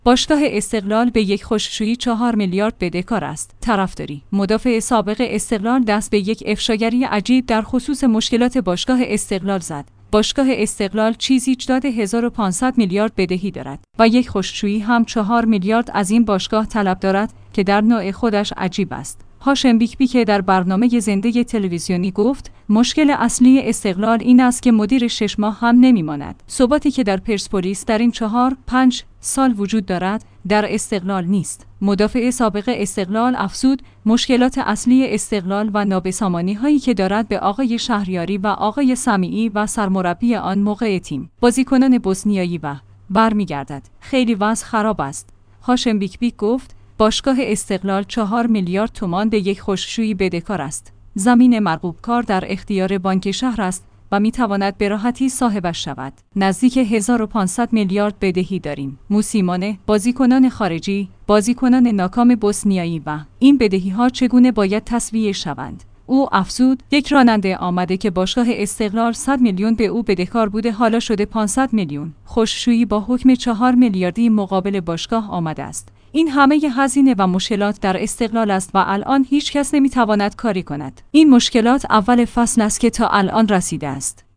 باشگاه استقلال چیزی جدود 1500 میلیارد بدهی دارد و یک خشک شویی هم 4 میلیارد از این باشگاه طلب دارد که در نوع خودش عجیب است. هاشم بیک زاده در برنامه زنده تلویزیونی گفت: مشکل اصلی استقلال این است که مدیر 6 ماه هم نمی‌ما